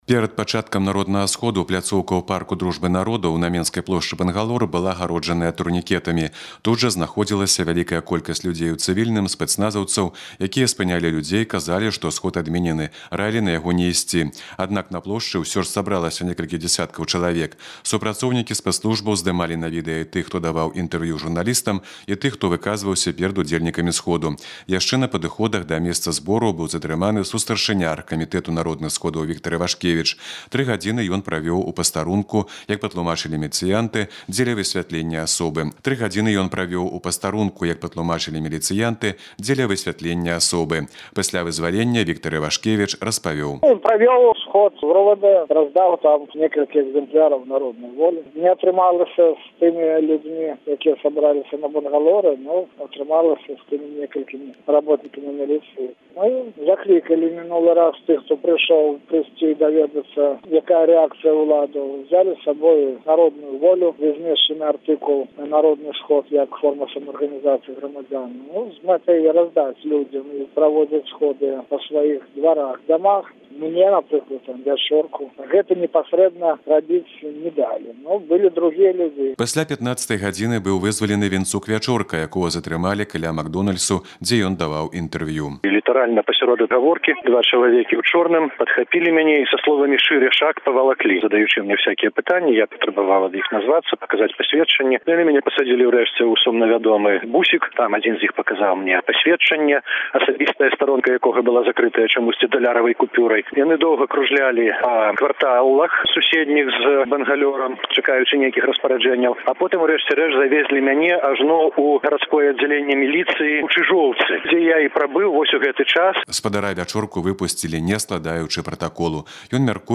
Выніковы рэпартаж